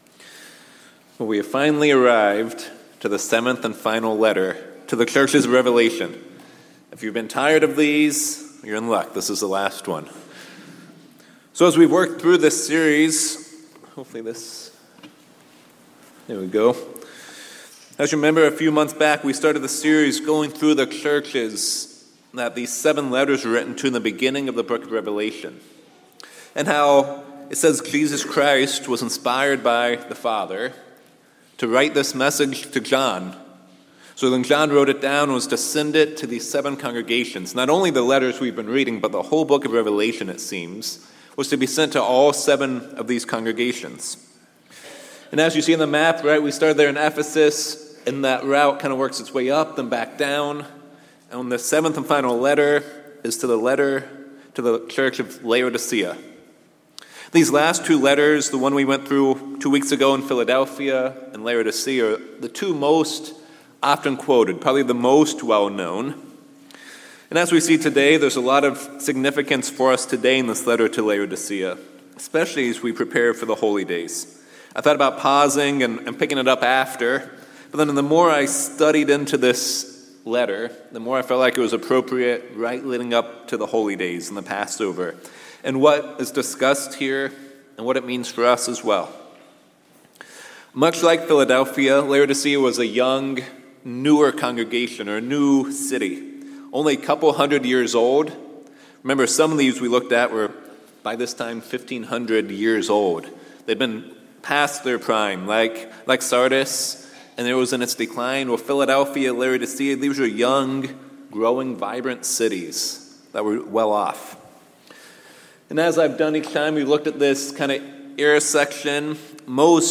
In part seven of the sermon series on the seven churches, we will read the letter to the church of Laodicea. They are known as the lukewarm church, neither cold nor hot, with no special properties or uses. We can learn the importance of examining ourselves, zealously turning back to God, and recognizing that He is our strength and hope.